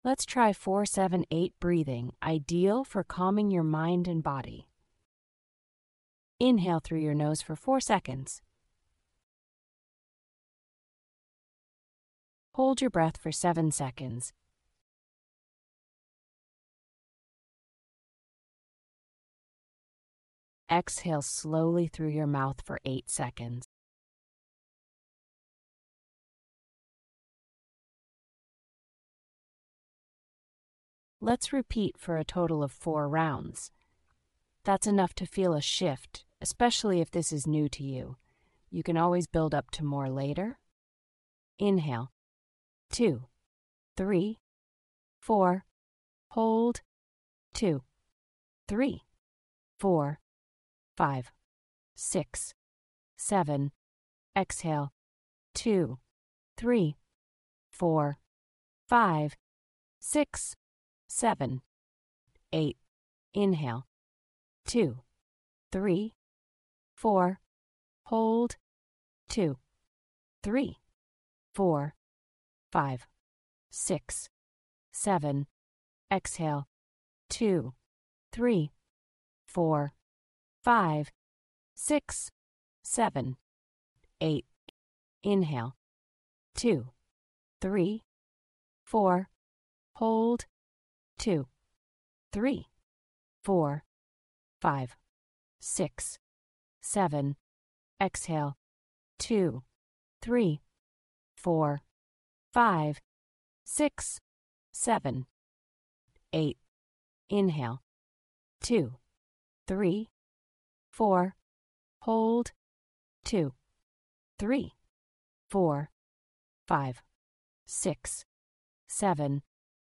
If you’re feeling tense, this short guided 4-7-8 breathing exercise is perfect for calming your mind and easing into a more relaxed state.